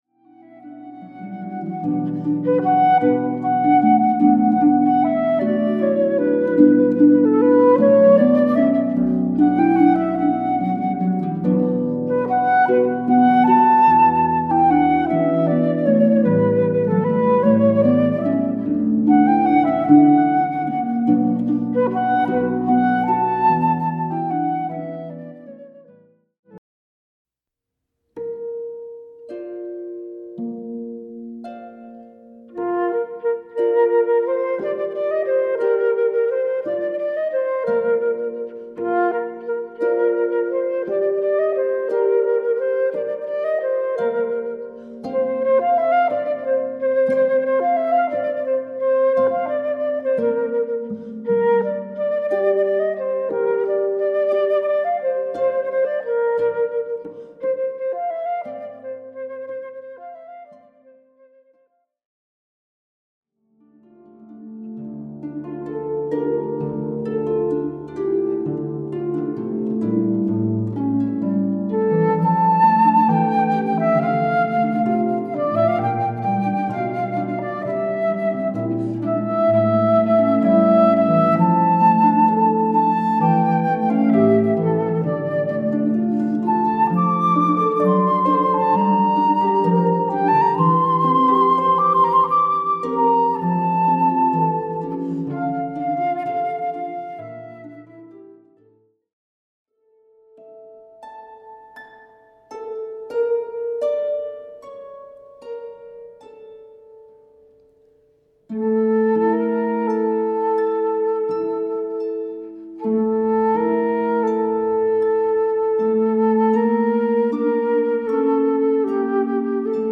for pedal harp and flute
seven Armenian folk tunes
flute